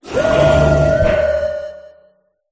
zamazenta_ambient.ogg